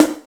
50 SNARE 2.wav